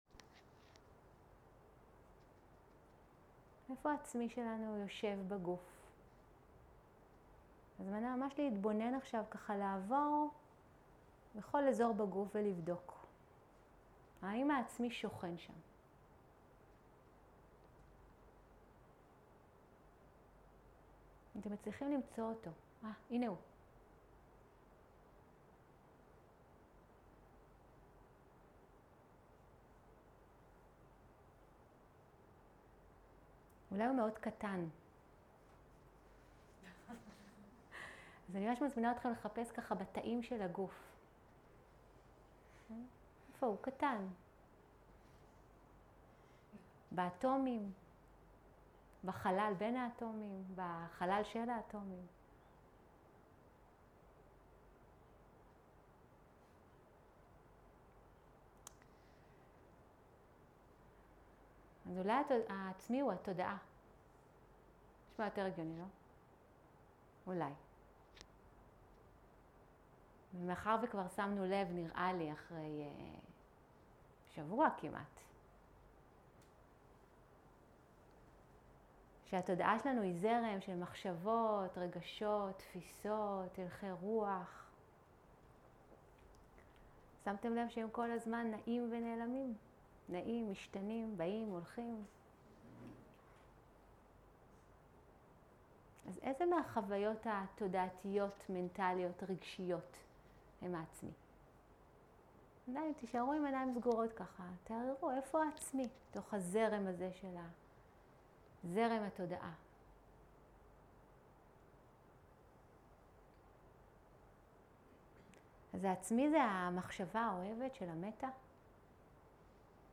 סוג ההקלטה: שיחות דהרמה
ריטריט 10 ימים